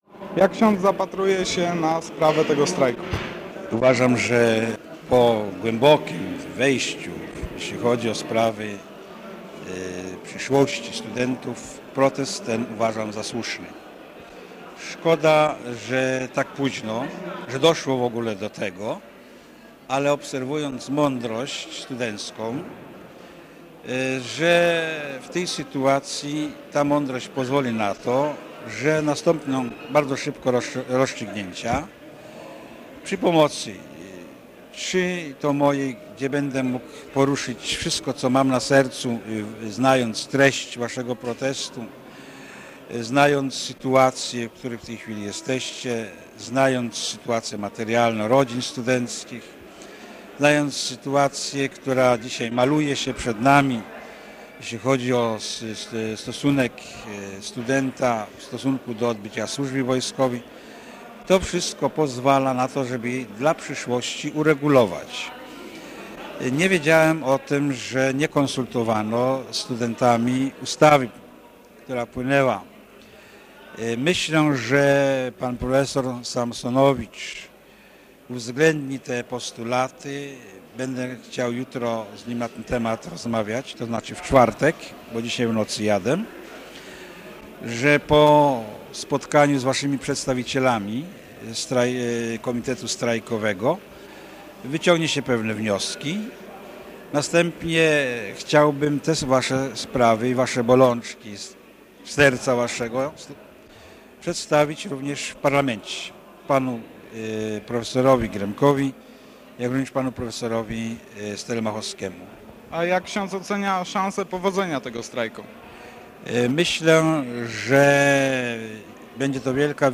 Wypowiedź ks. prałata Henryka Jankowskiego nt. protestu studentów